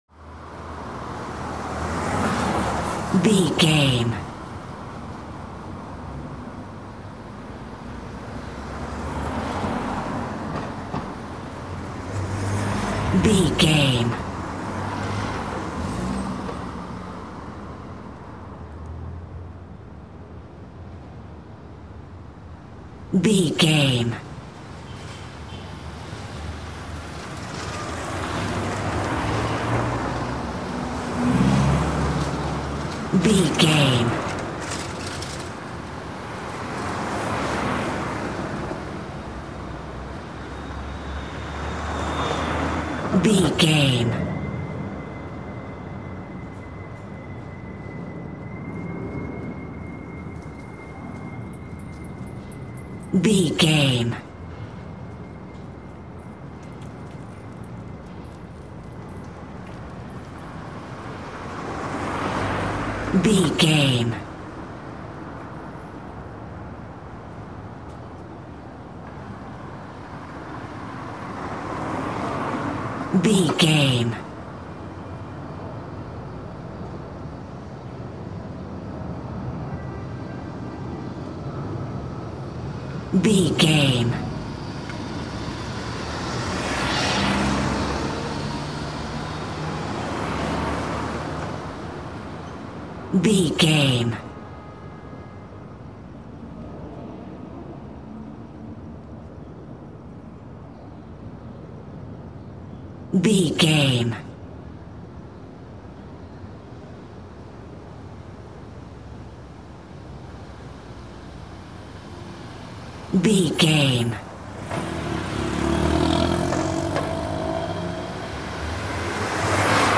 City rumble calm street
Sound Effects
urban
calm
ambience